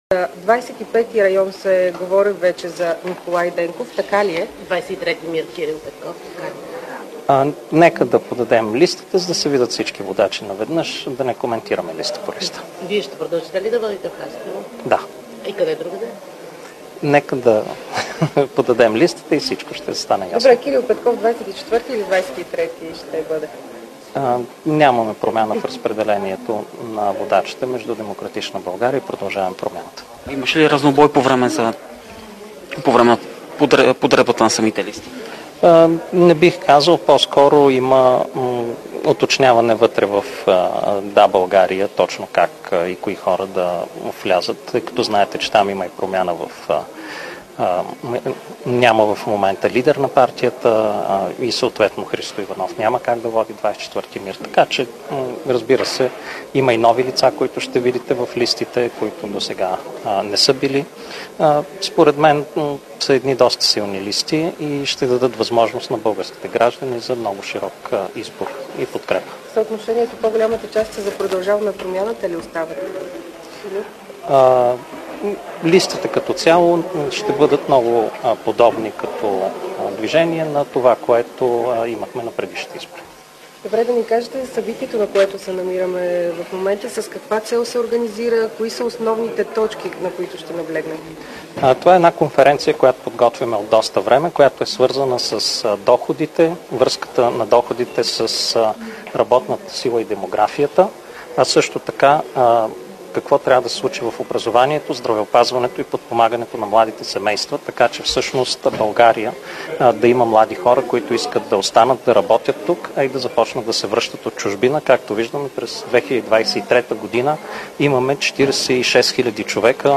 Директно от мястото на събитието
10.05 - Брифинг на съпредседателя на ПП Асен Василев преди конференция на тема "Капиталът на бъдещето". - директно от мястото на събитието (хотел "Интерконтинентал", бална зала)